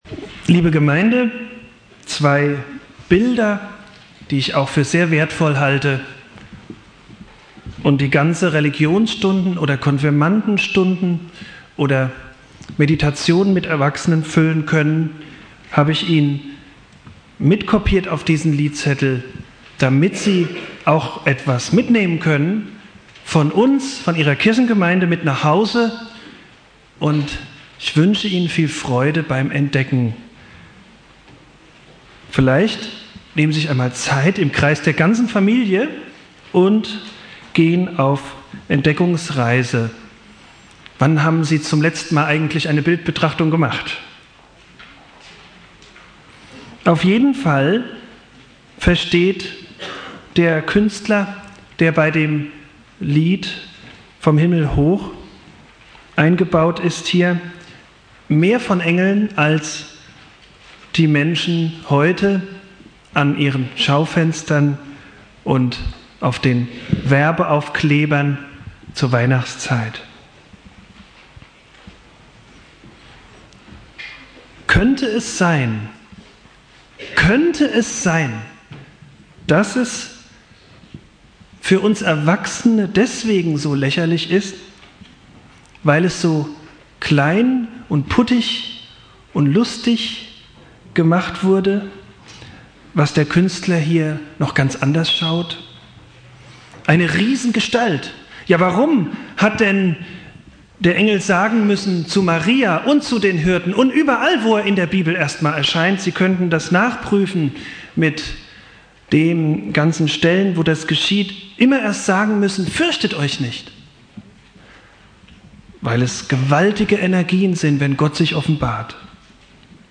Predigt
Heiligabend Prediger